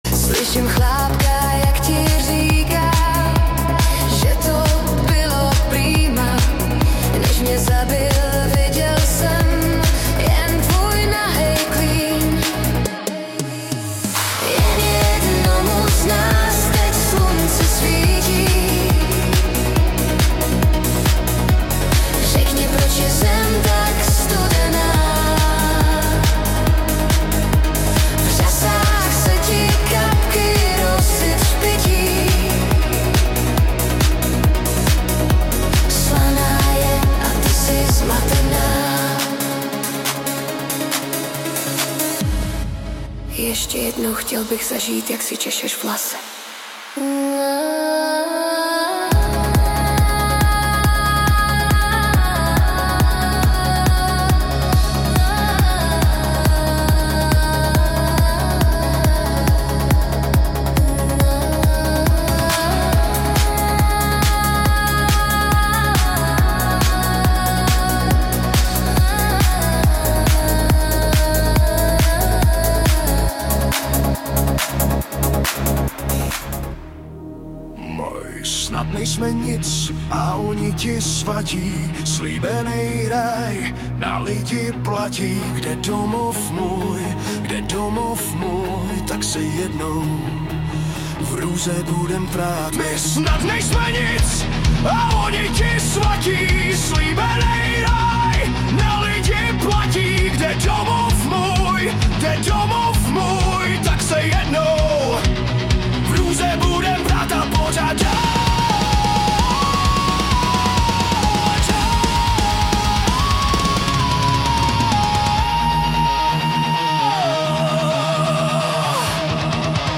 Genre:Greatest Hits